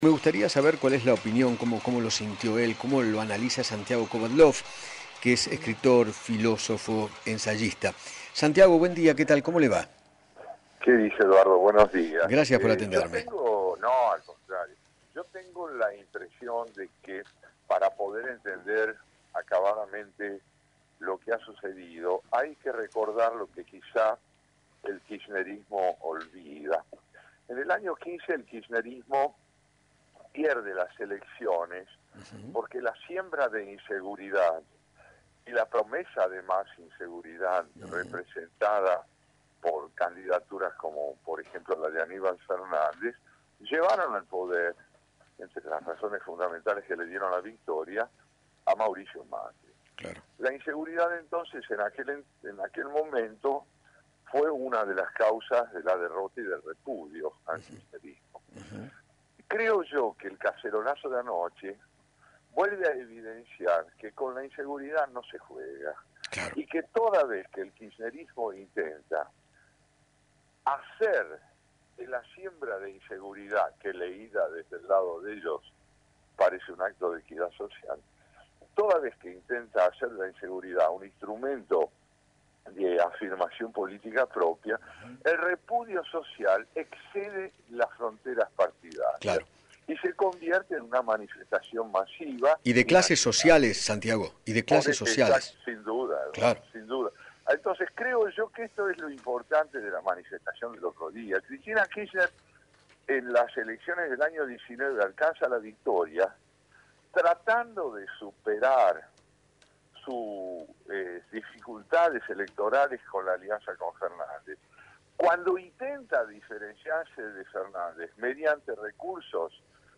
Santiago Kovadloff, escritor y filósofo, dialogó con Eduardo Feinmann y analizó los cacerolazos que se escucharon anoche en contra de los arrestos domiciliarios.